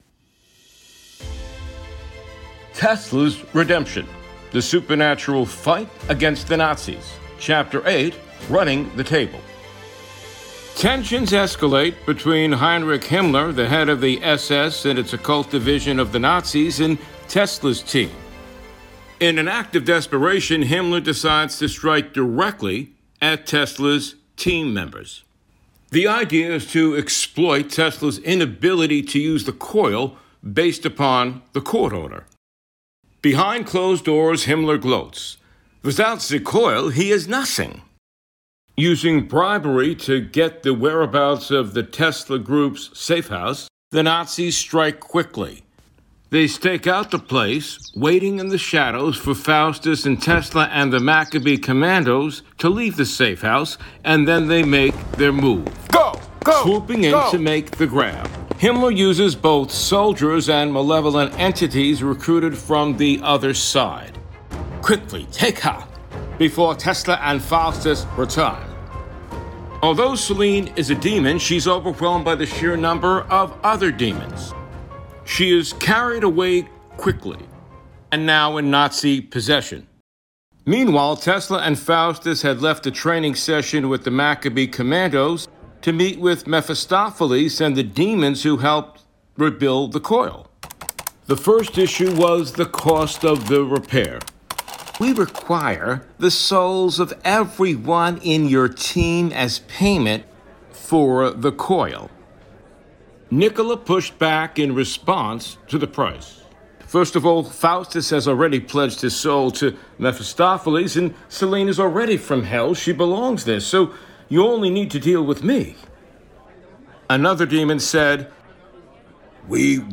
Format: Audio Book
Voices: Solo
Narrator: Third Person
Soundscape: Sound effects & music